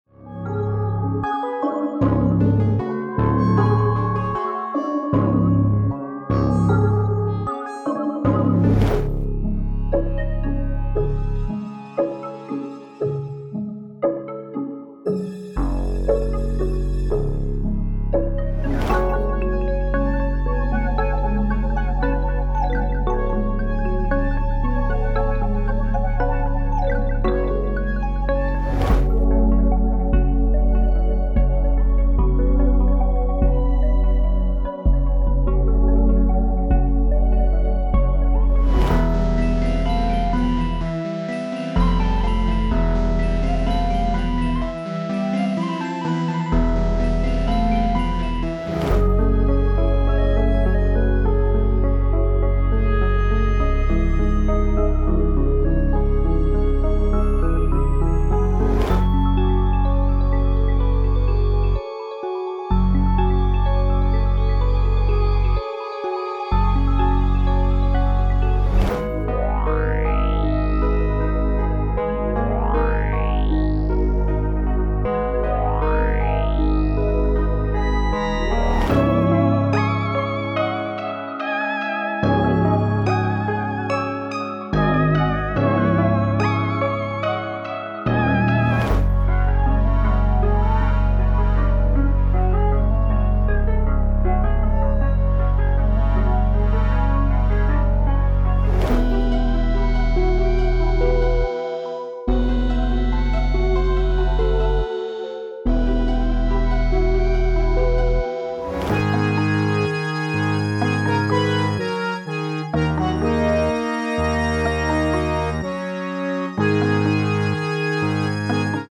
Preseting Trust Issues! An Omnisphere preset bank that captures the modern trap sound of artists like future, metro boomin, and more
• 12 Melody Samples (with stems)